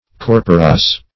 corporace - definition of corporace - synonyms, pronunciation, spelling from Free Dictionary
Corporace \Cor"po*race\ (k?r"p?-r?s), n.